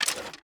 Pick Up Arrows A.wav